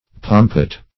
pompet - definition of pompet - synonyms, pronunciation, spelling from Free Dictionary Search Result for " pompet" : The Collaborative International Dictionary of English v.0.48: Pompet \Pom"pet\, n. [OF. pompette.]
pompet.mp3